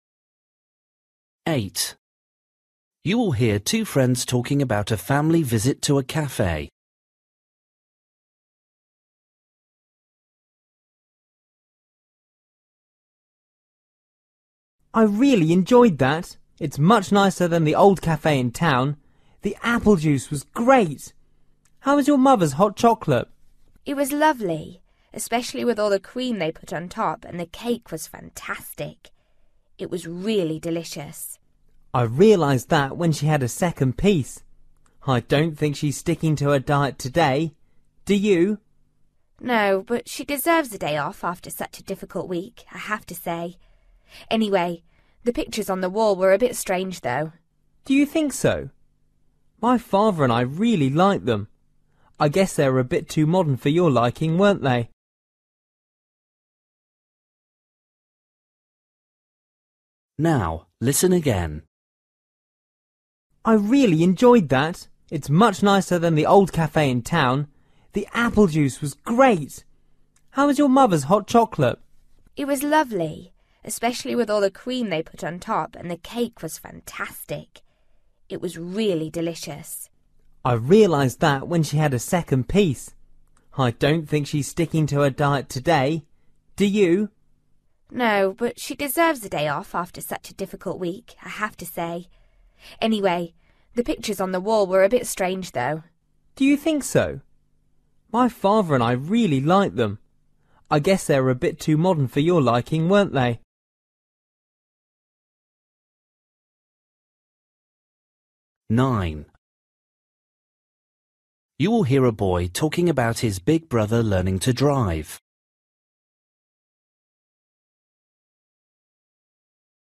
Listening: everyday short conversations
8   You will hear two friends talking about a family visit to a cafe. What did the girl’s mother like best?
9   You will hear a boy talking about his big brother learning to drive. How did the boy’s brother feel about his first lesson?
13   You will hear two friends talking about a new teacher. They think the new teacher is